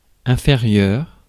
Ääntäminen
France: IPA: [ɛ̃.fe.ʁjœʁ]